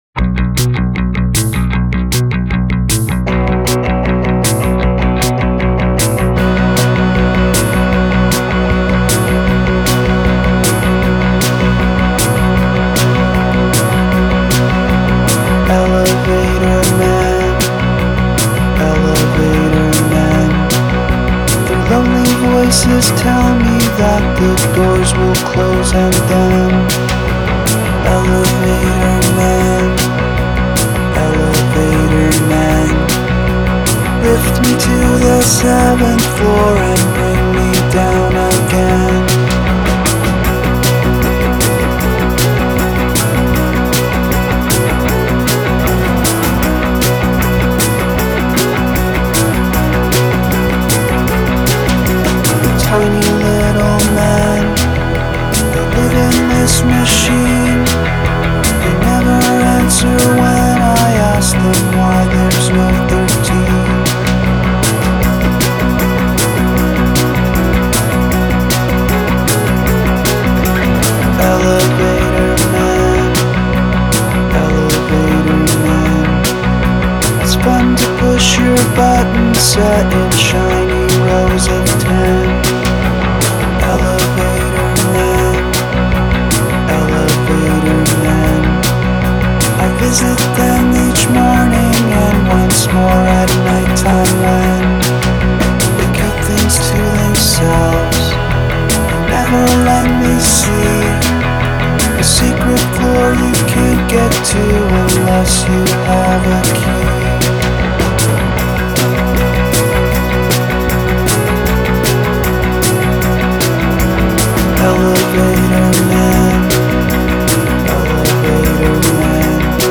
Prominent musical repetition
Cute drum sounds. Nice synth.